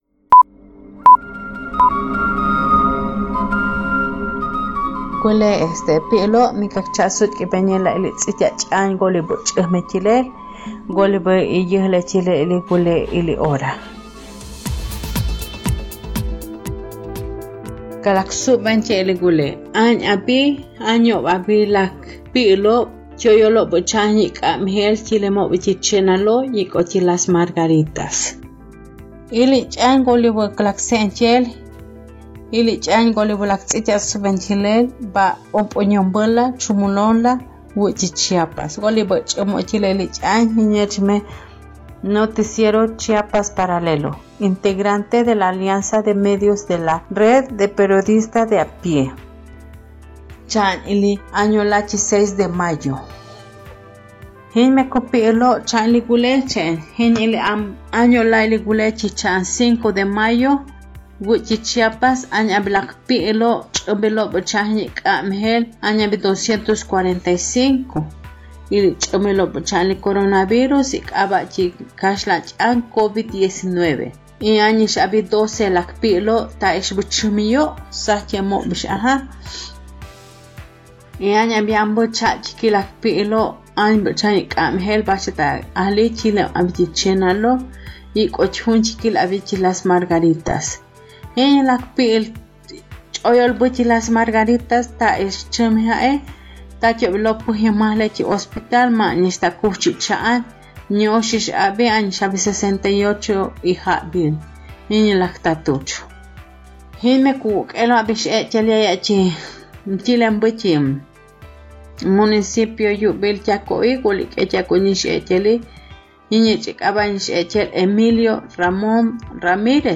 Traducción y locución: